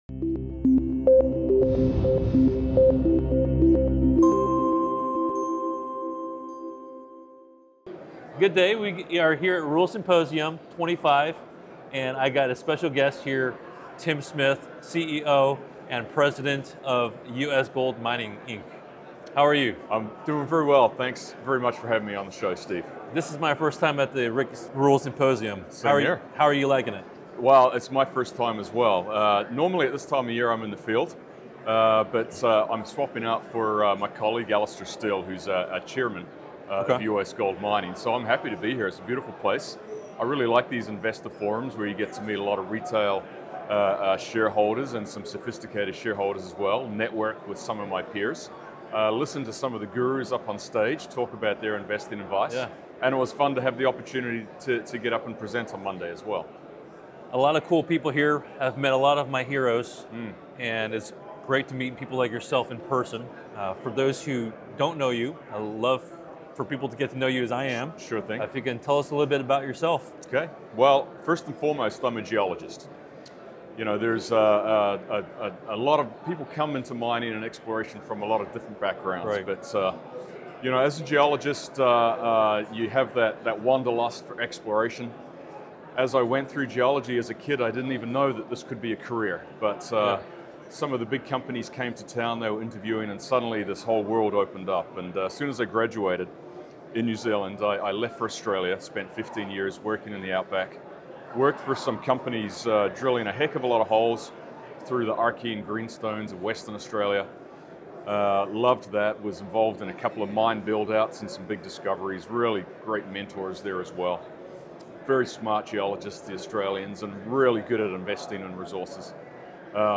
Exciting Gold Discoveries & Future Prospects: Interview